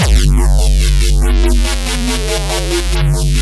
weird phase reese.wav